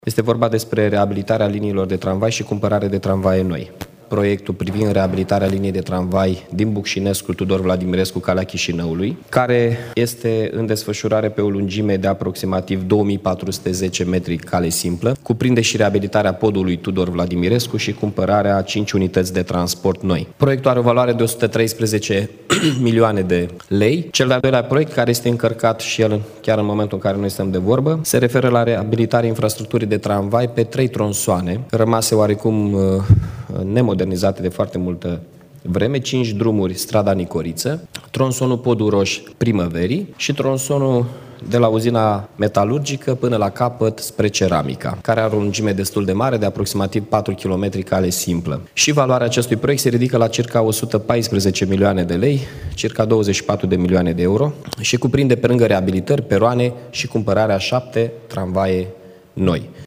Primarul municipiului Iași, Mihai Chirica